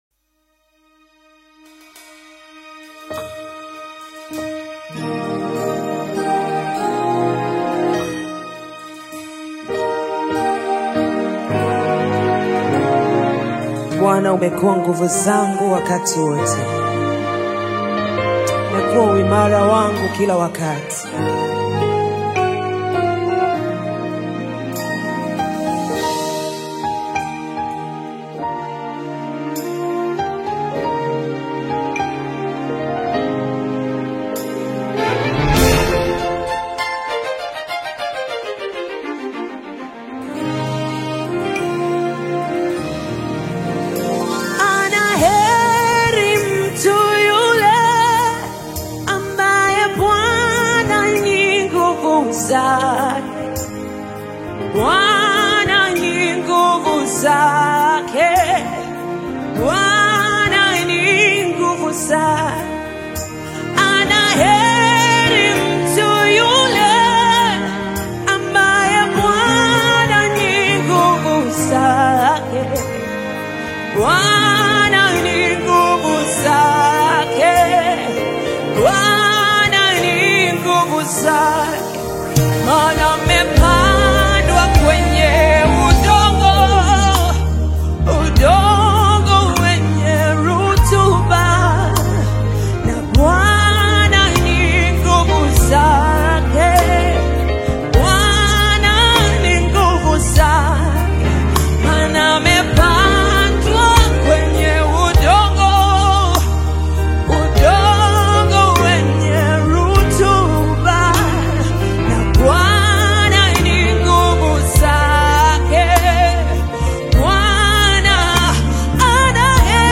Pulsating with soulful energy
soaring harmonies